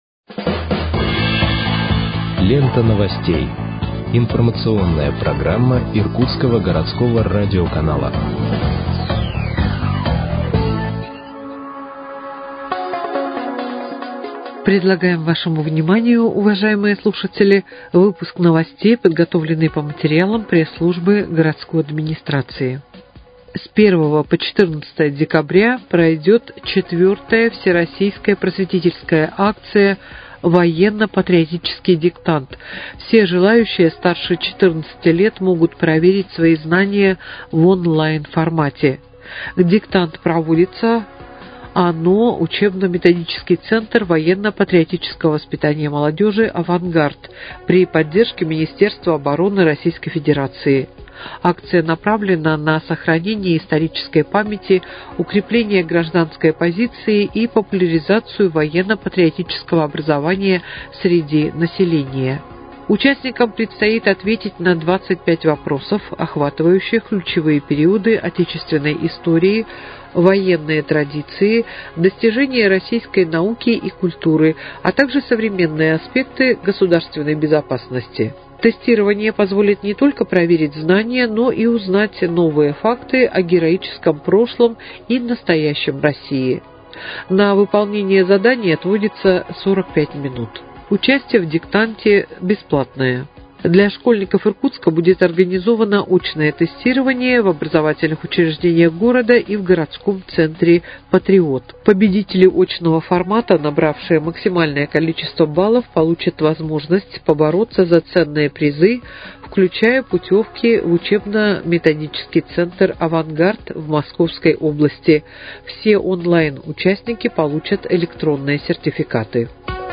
Выпуск новостей в подкастах газеты «Иркутск» от 25.11.2025 № 1